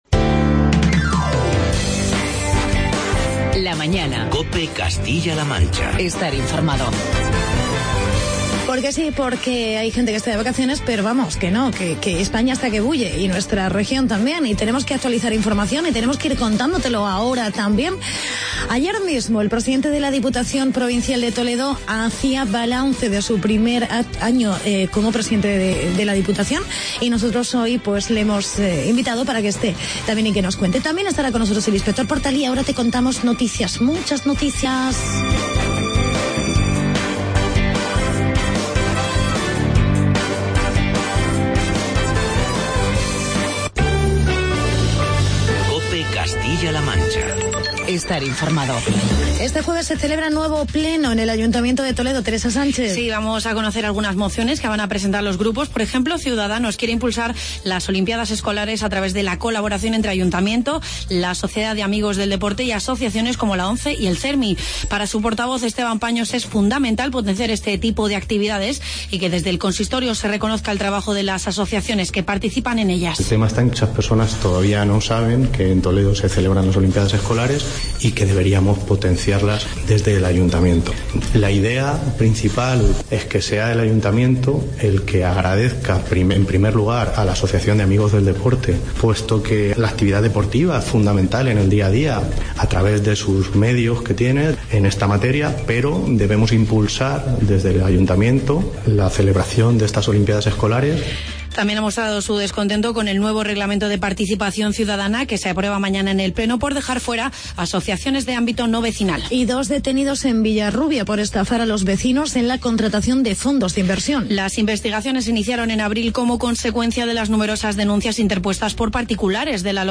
Entrevista con el Pte de la Diputación Provincial de Toledo, Álvaro Gutierrez y "Policía Nacional"...